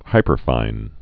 (hīpər-fīn)